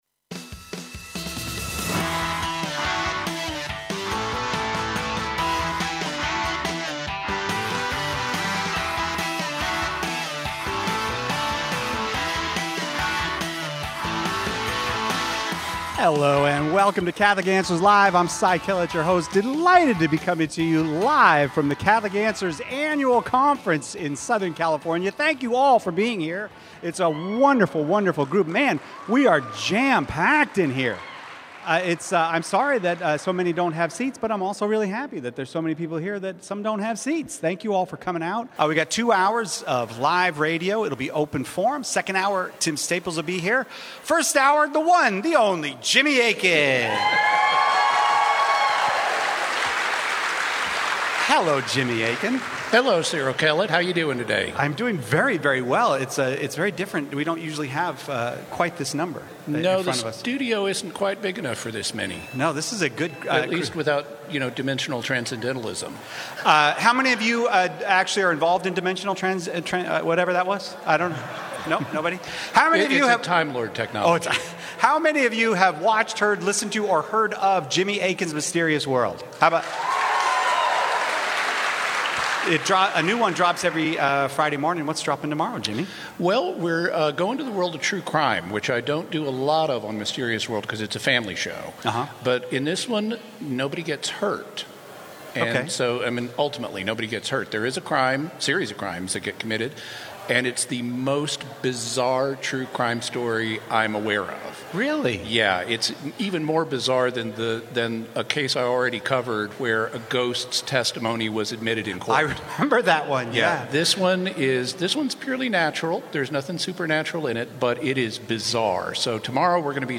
Live from the Catholic Answers Conference